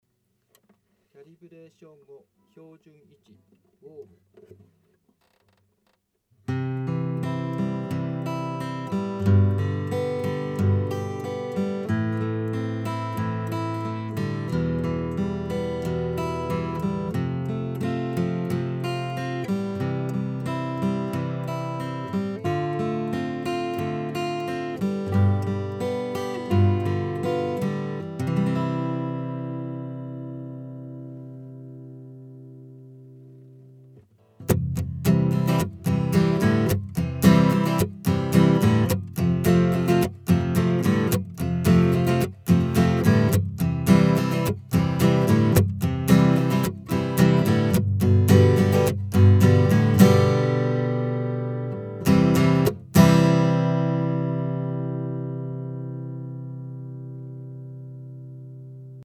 直接オーディオIFに入れてエフェクト類は全くかけていません。
キャリブレーション後（WARM）
キャリブレーション時の弾き方でずいぶん音が変わる。
iRig-Calib-Center-WARM.mp3